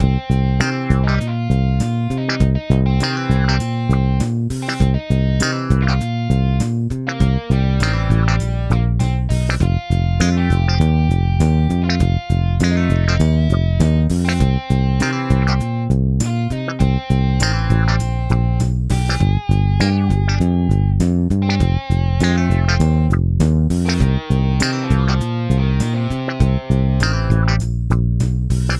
Lesson 5: Creating 12 Bar Blues
lesson-5-example-blues.wav